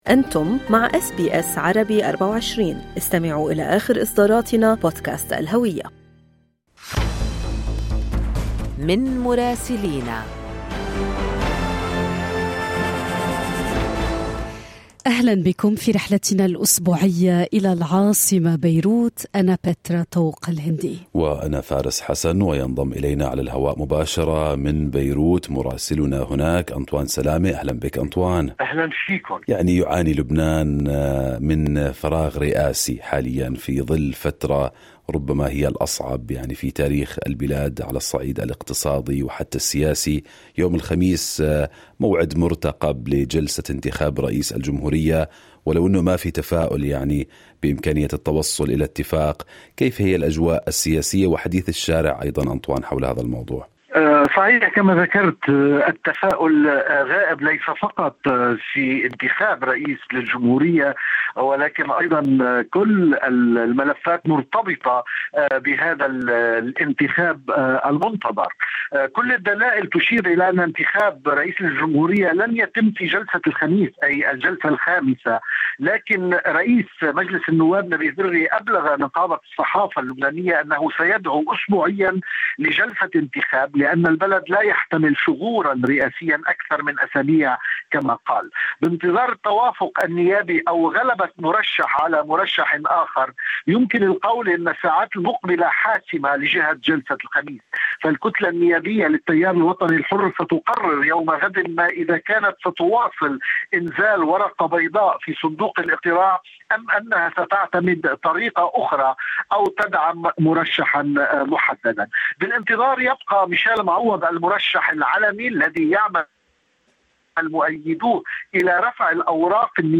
يمكنكم الاستماع إلى تقرير مراسلنا في لبنان بالضغط على التسجيل الصوتي أعلاه.